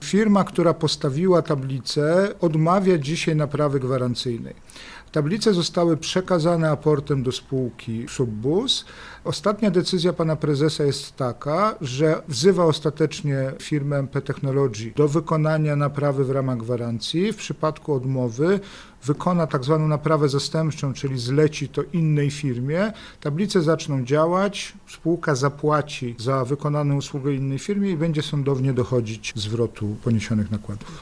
– Niestety wykonawca nie chce dokonać naprawy w ramach gwarancji – powiedział Jacek Milewski wiceprezydent Nowej Soli: